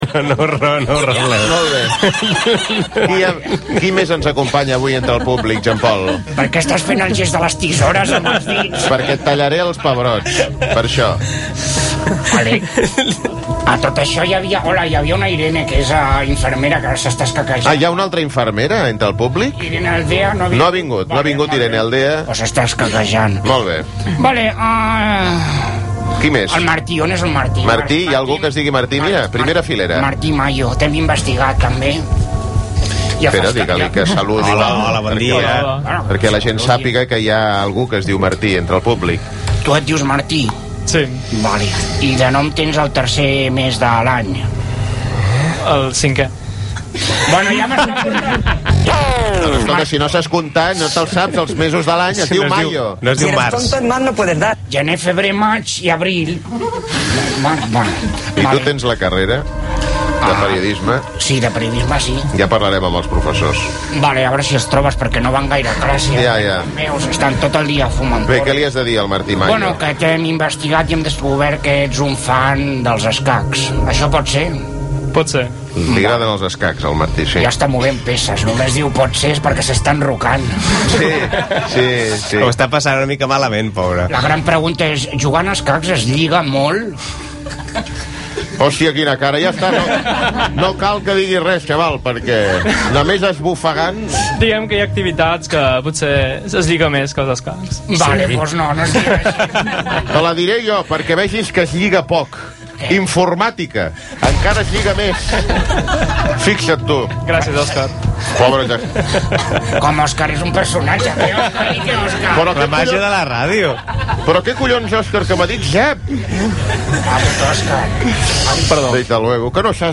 El nostre jugador és entrevistat a Rac1
De la mà dels locutors Òscar Dalmau (també presentador de "El Gran Dictat" a TV3) i Òscar Andreu el programa treu punta de l'actualitat amb un sentit de l'humor trinxeraire.
En un moment del programa els locutors pregunten a persones del públic a què es dediquen, què els agrada...